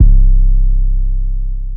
808s
basic spinz 808.wav